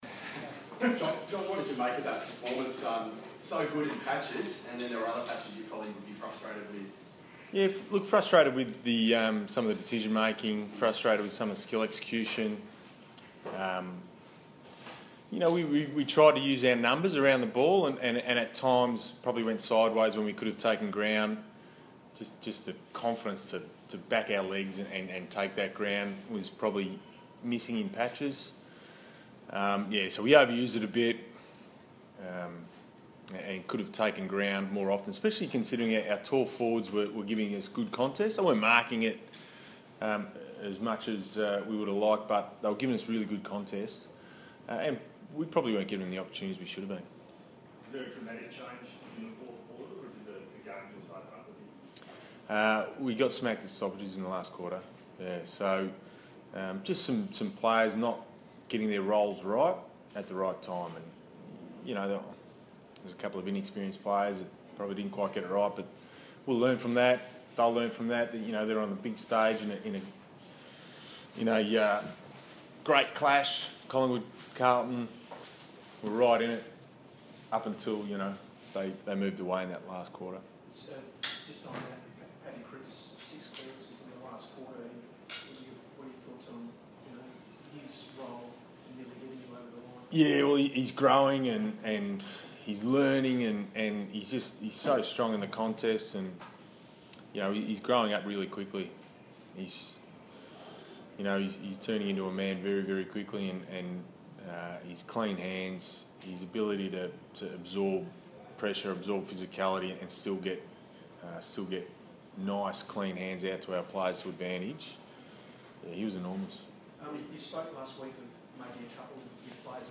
Round 19 post-match press conference